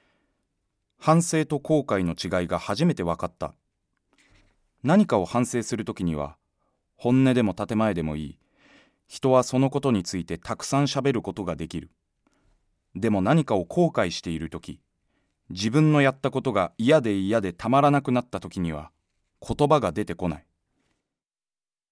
朗読
ボイスサンプル